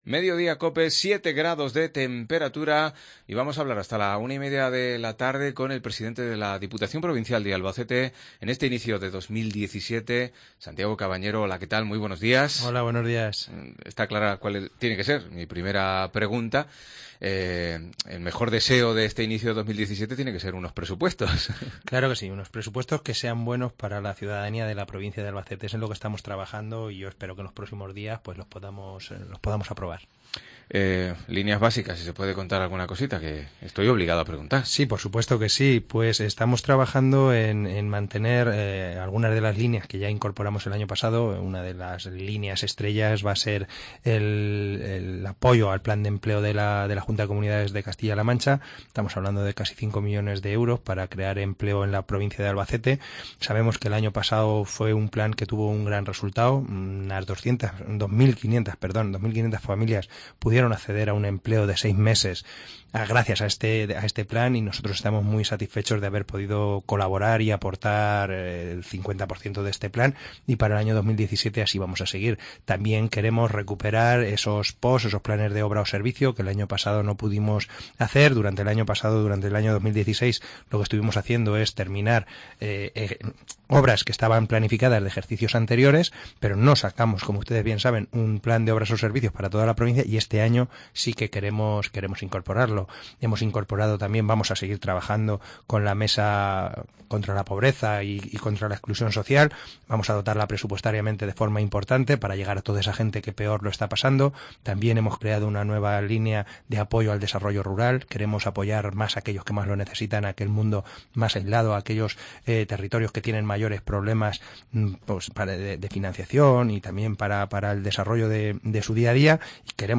170110 Entrevista Santiago Cabañero